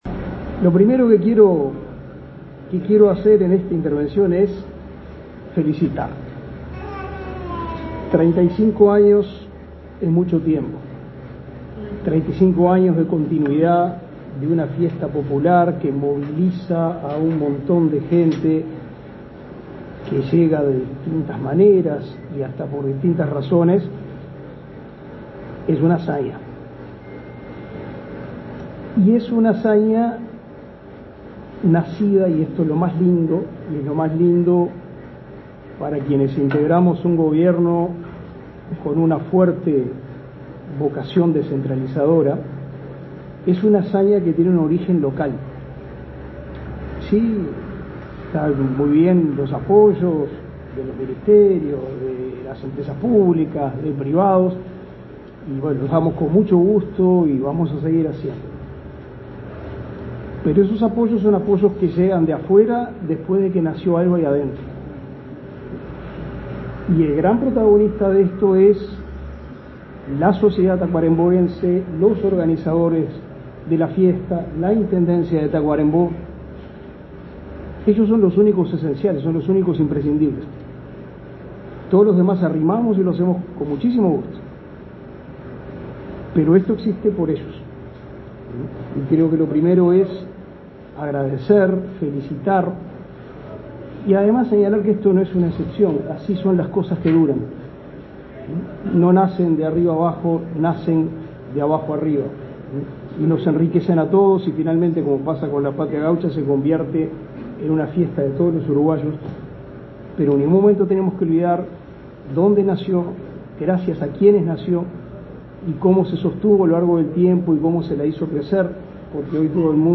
Palabras del ministro de Educación y Cultura
El ministro de Educación y Cultura, Pablo da Silveira, participó este miércoles 9 en el lanzamiento de la 35.ª edición de la Fiesta de la Patria